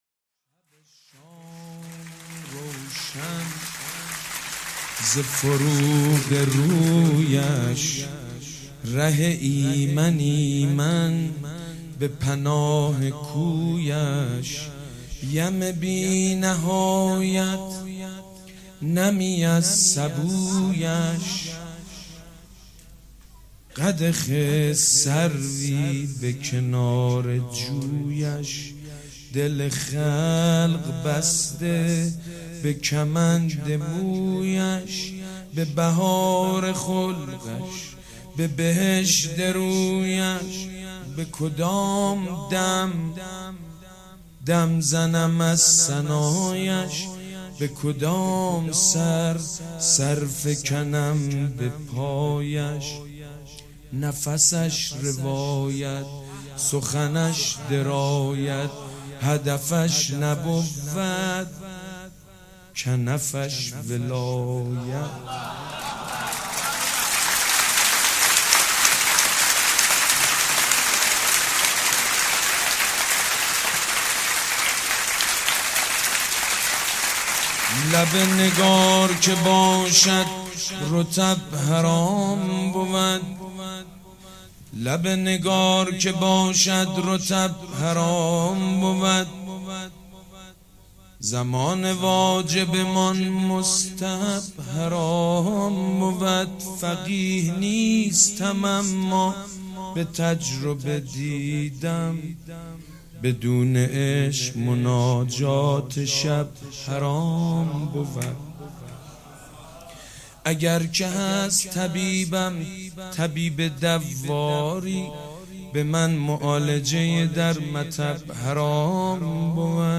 مولودی جدید حاج سید مجید بنی فاطمه میلاد پیامبر اکرم (ص) و میلاد امام جعفر صادق (ع) حسینیه ی ریحانة الحسین شنبه 2 آذر ماه ۱۳۹۷
شعرخوانی